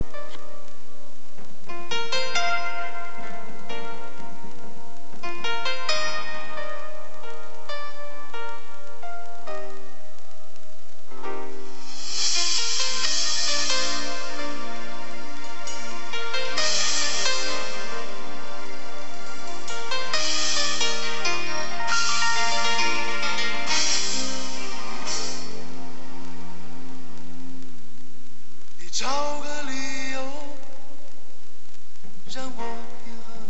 16k采样率实测（手机外放，Max9814录音）
背景声里的 “咚咚” 是50m外的装修施工，这个 Max9814 的放大效果还是很牛的。